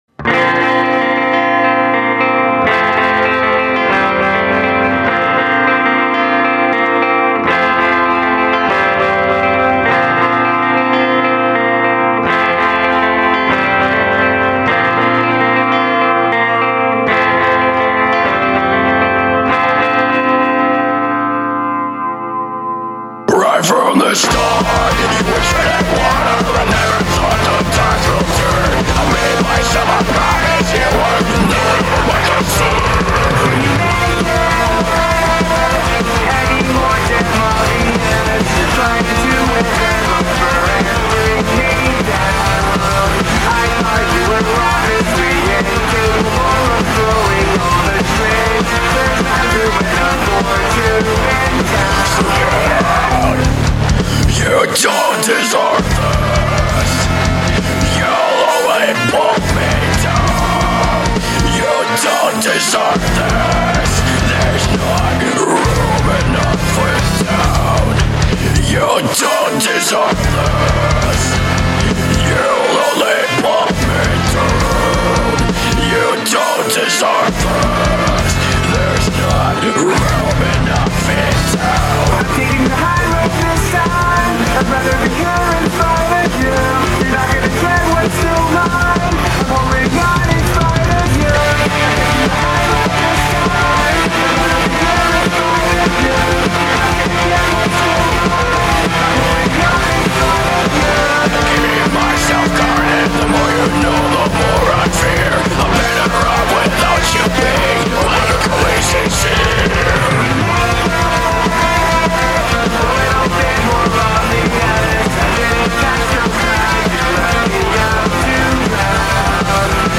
Brony musicans
I will do the screaming (Trixie)